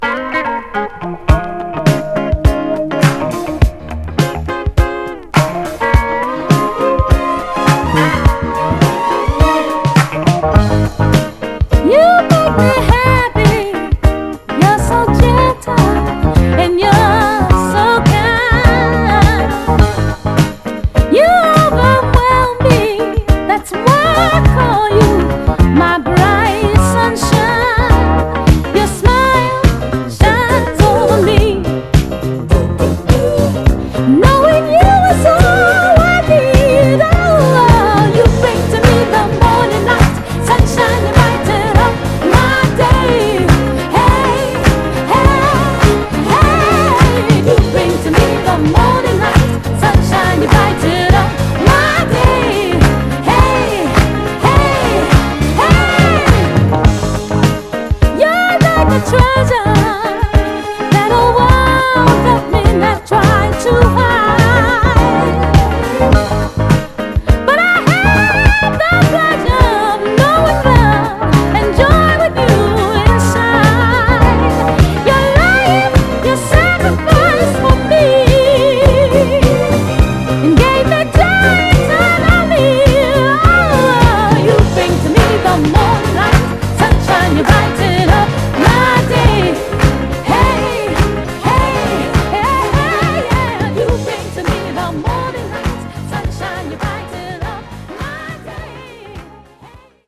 幅広いフロアで活躍する、爽やか軽快なモダン・ソウル・ステッパー〜ソウル・ダンサーです！
※試聴音源は実際にお送りする商品から録音したものです※